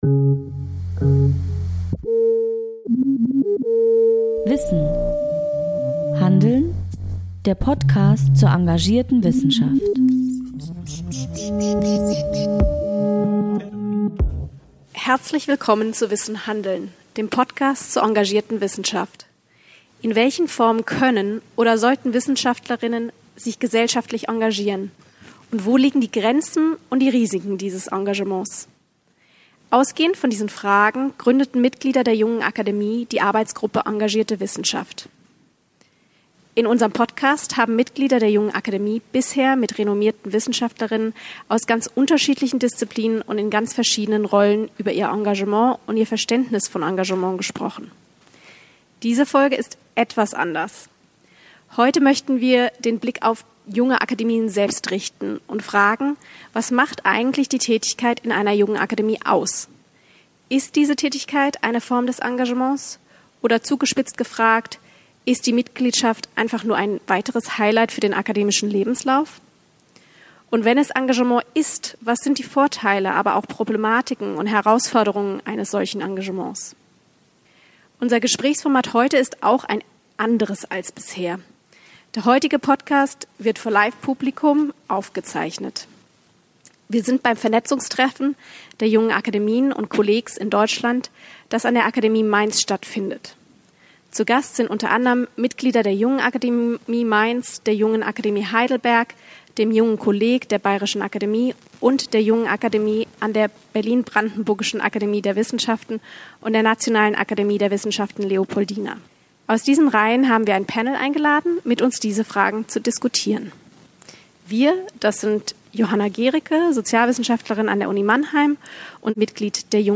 Auftakt des Treffens bildete eine Podiumsdiskussion zur Engagierten Wissenschaft, deren Live-Mitschnitt die Grundlage bildet...